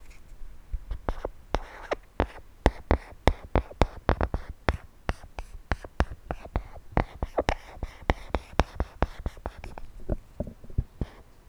whiteboard_writing.wav